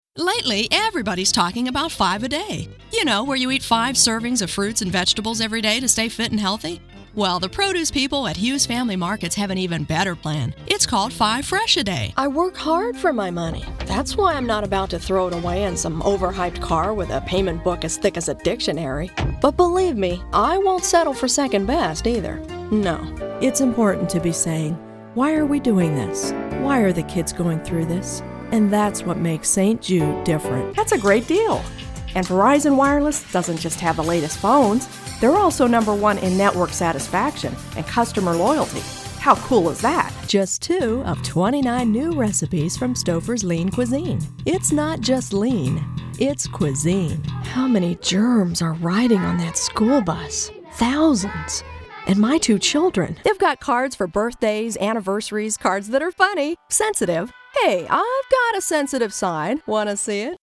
Conversational, Real Person, Sincere, Genuine, Expressive
middle west
Sprechprobe: Sonstiges (Muttersprache):